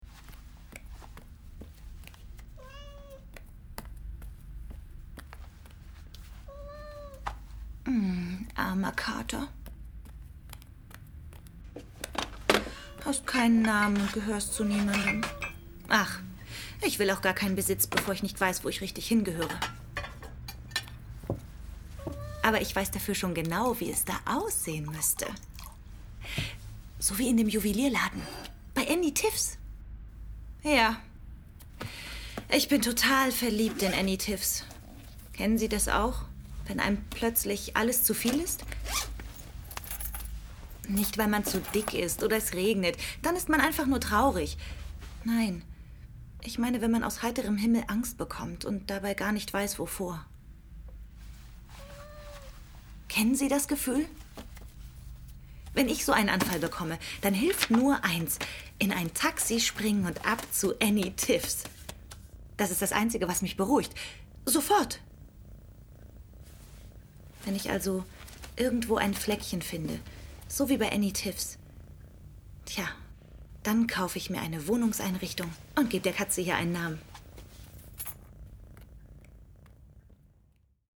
Stimmproben
Eichmann Werbung